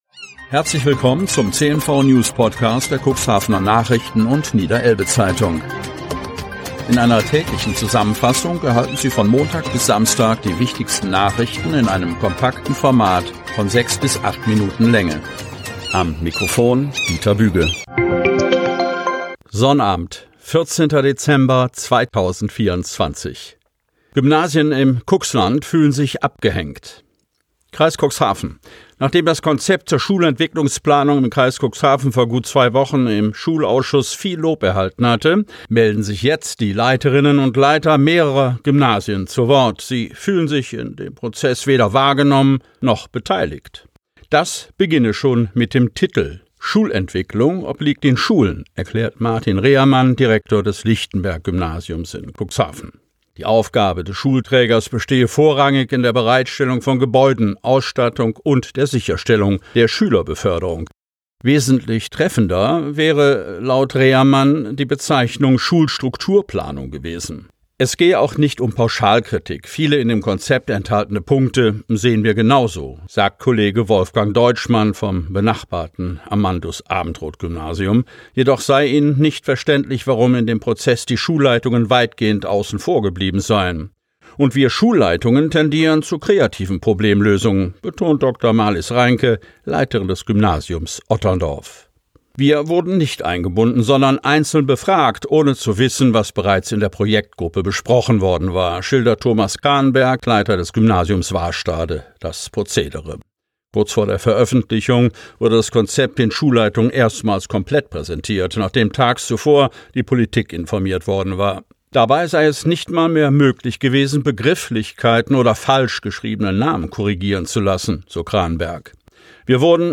Ausgewählte News der Cuxhavener Nachrichten + Niederelbe-Zeitung am Vorabend zum Hören!